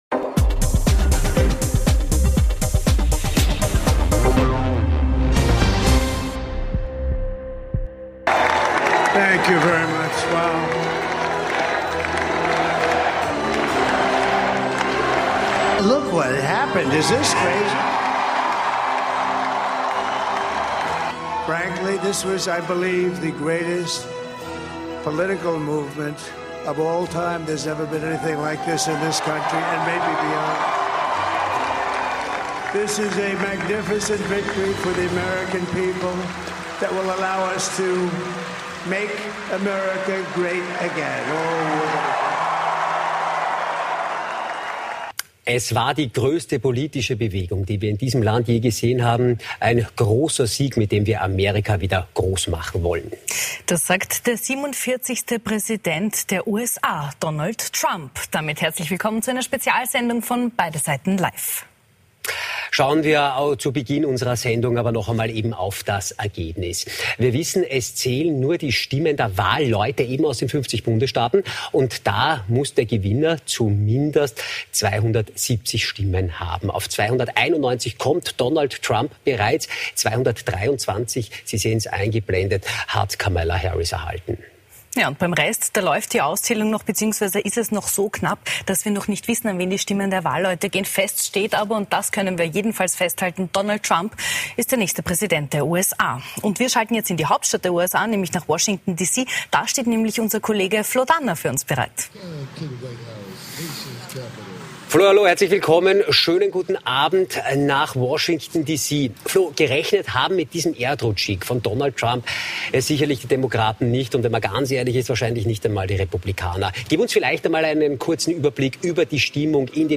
Und nachgefragt haben wir heute bei gleich drei Gästen - Im Interview des Tages ist heute ÖVP-Außenminister Alexander Schallenberg zu Ga...